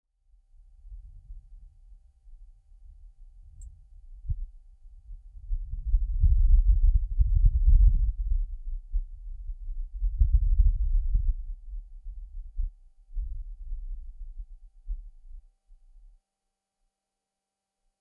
È il VENTO su Marte.
2030_vento_marte.mp3